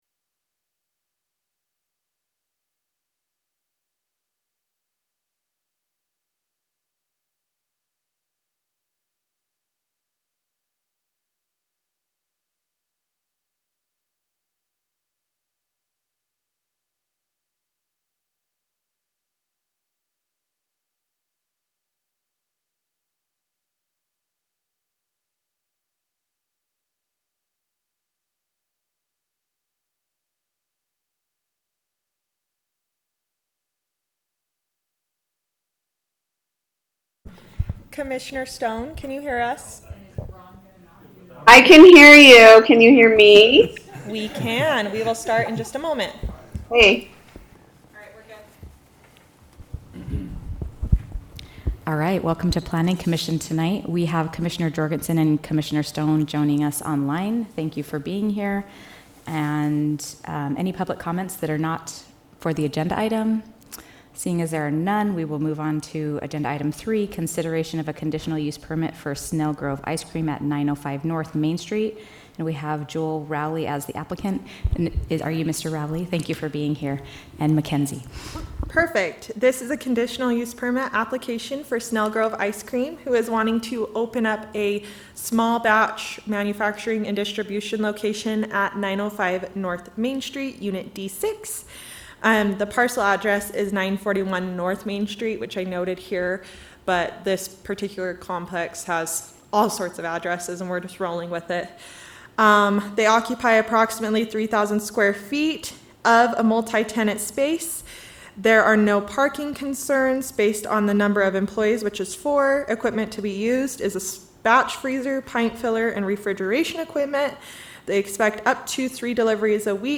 Notice, Meeting
Some members of the Planning Commission may participate electronically.